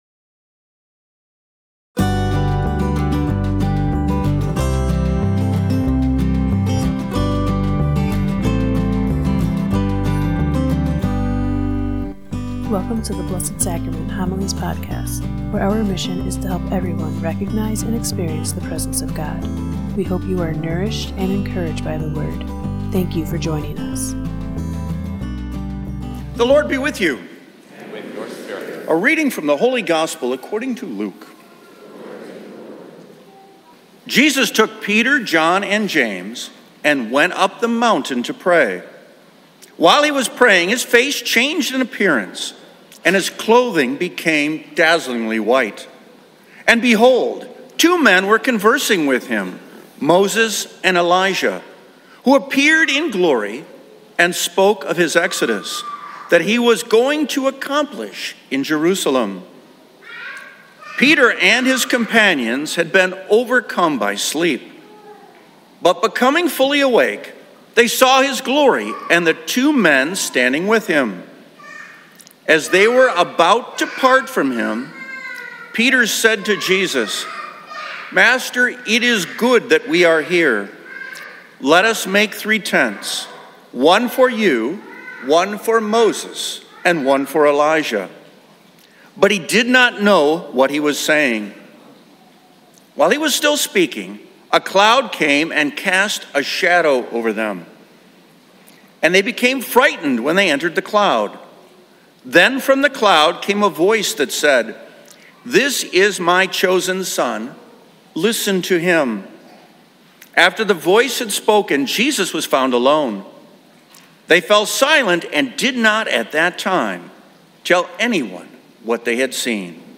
Blessed Sacrament Parish Community Homilies